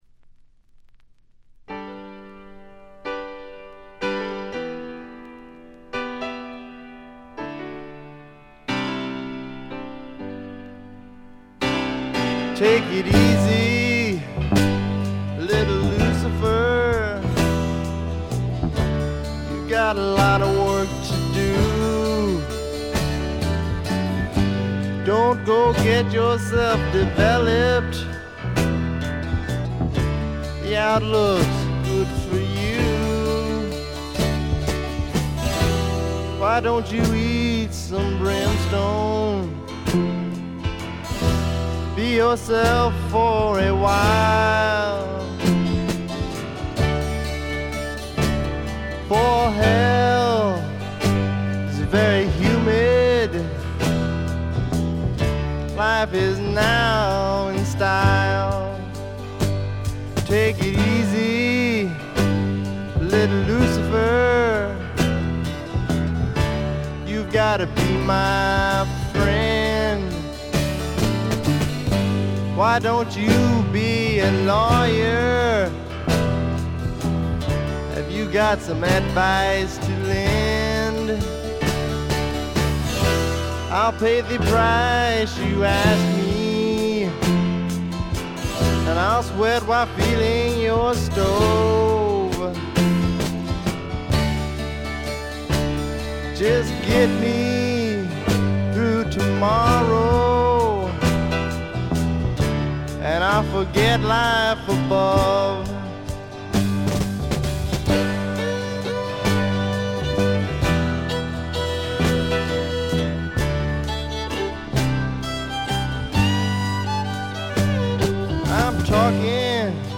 部分試聴ですがごくわずかなノイズ感のみ。
質感は哀愁のブリティッシュ・スワンプそのまんまであります。
試聴曲は現品からの取り込み音源です。